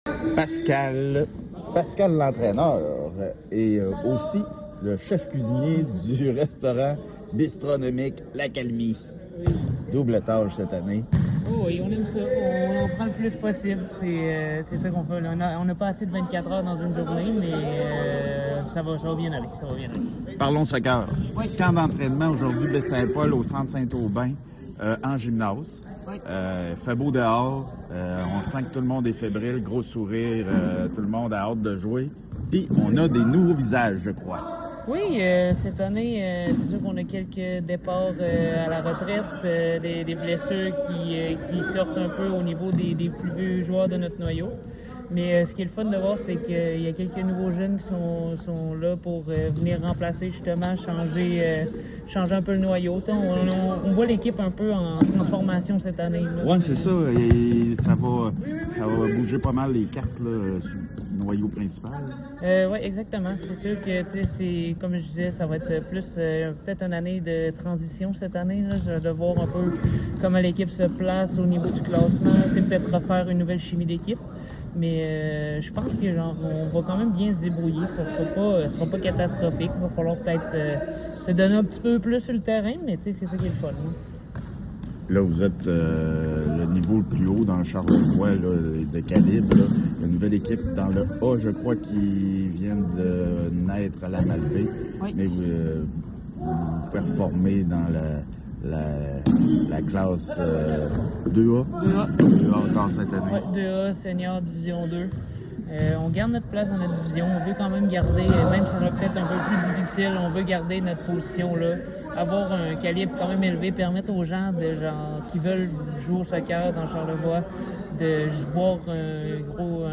« On voit l’équipe un peu en transformation cette année. Ça va être peut-être plus une année de transition de voir comment l’équipe se place au niveau du classement et refaire une nouvelle chimie d’équipe » a confié l’entraîneur lors d’une entrevue réalisée sur le terrain.